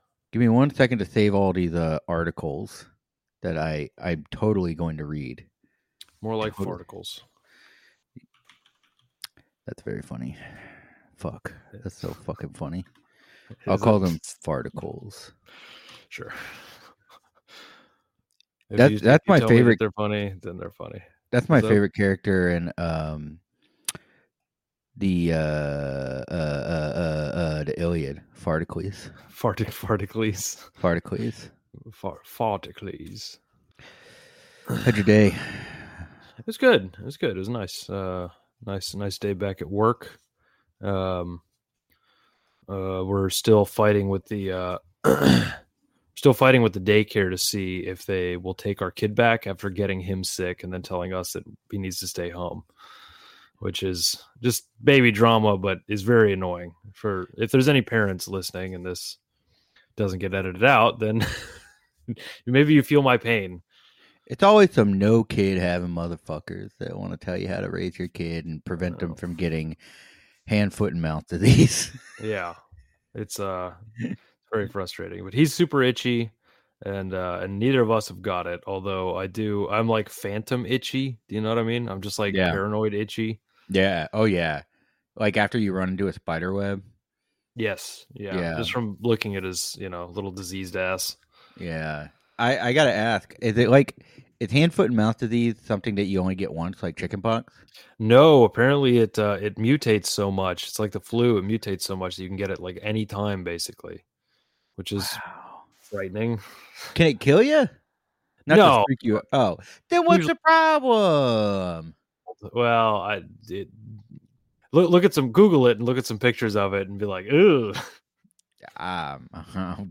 Two lifelong friends and propaganda lovers from the Gulf Coast look at and discuss media portrayals of politics from ongoing news media narratives to film and television.